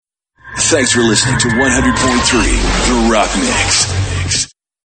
Radio Imaging & Voiceover